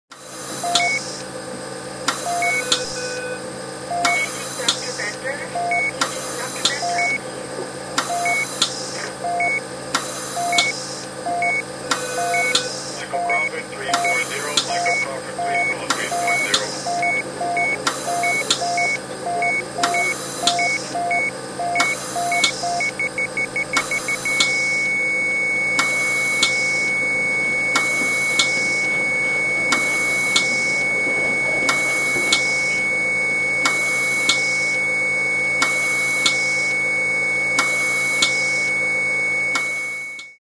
Звуки медицинской аппаратуры для реанимации легких